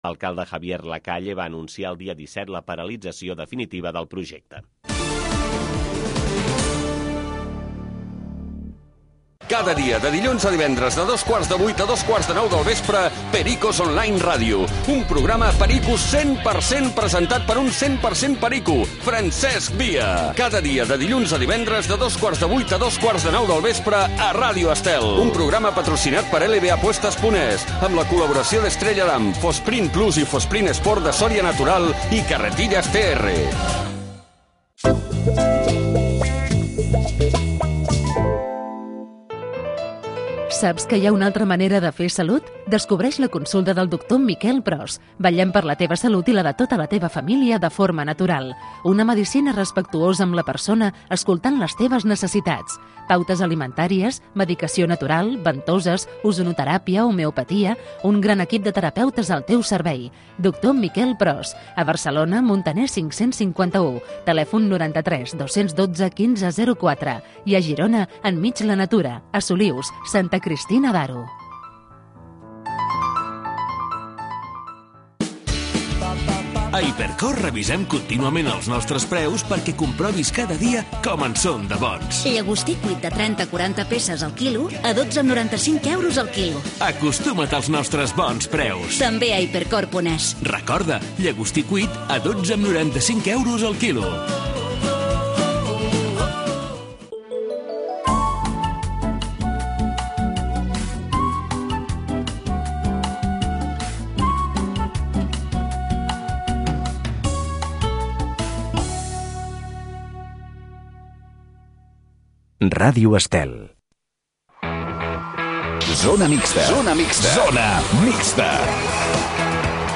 Zona mixta. Programa diari dedicat al món de l'esport. Entrevistes amb els protagonistes de l'actualitat poliesportiva.